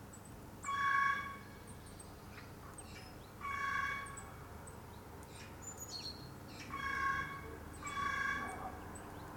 Corvo pequeno
Corvus corone
Canto